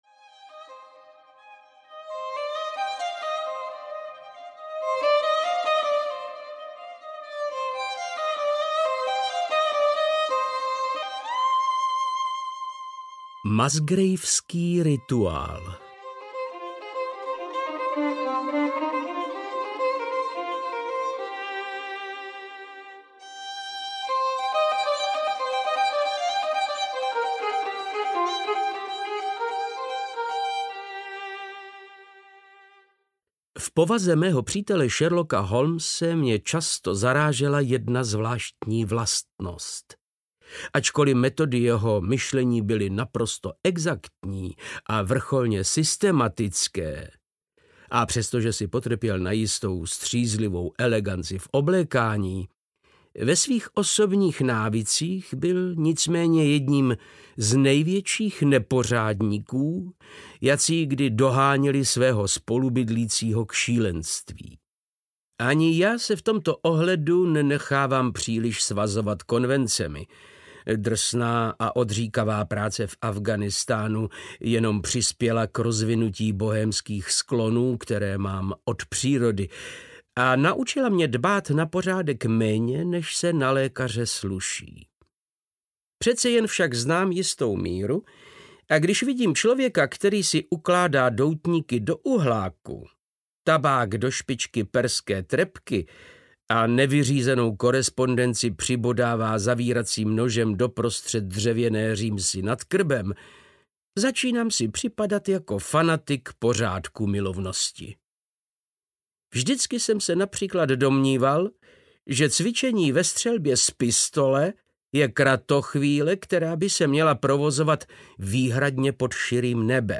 Vzpomínky na Sherlocka Holmese 5 - Musgraveský rituál audiokniha
Ukázka z knihy
• InterpretVáclav Knop